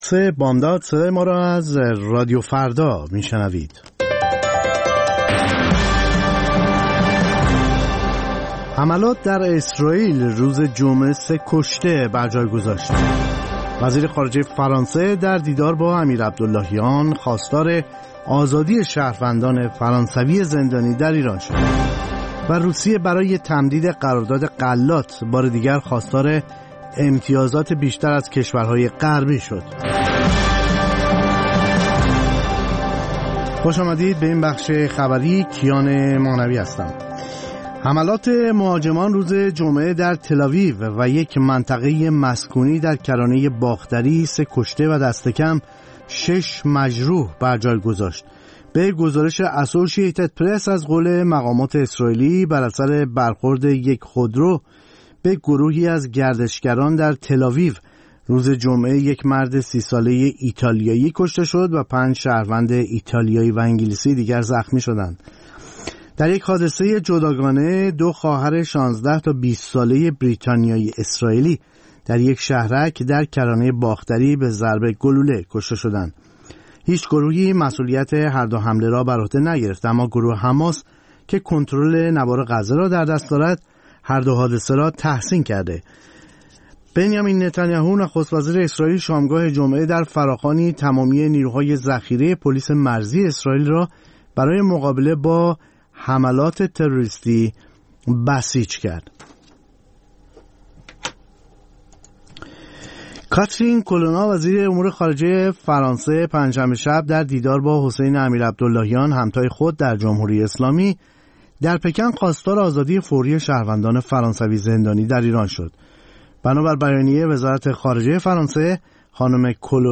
سرخط خبرها ۳:۰۰